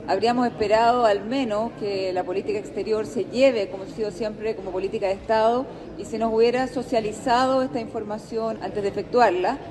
Desde la Comisión de Relaciones Exteriores de la Cámara, la diputada Catalina Del Real afirmó que, tratándose de una definición de Estado, el presidente Boric debió haber socializado previamente la decisión.